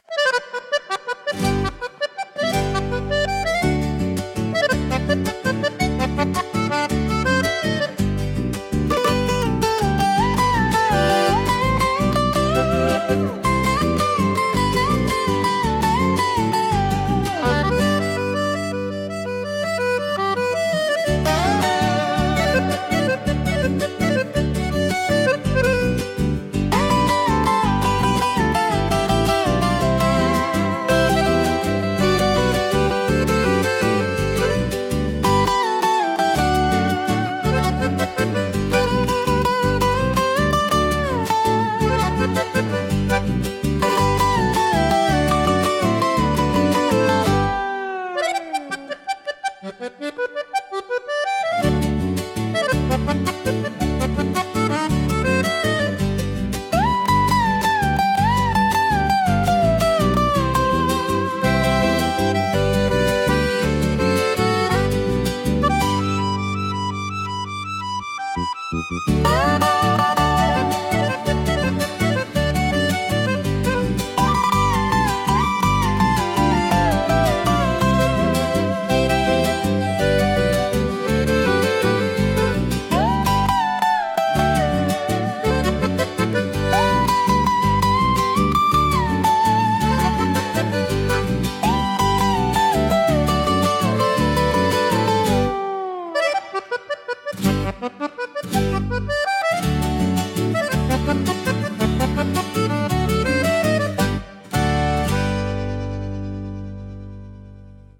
instrumental 11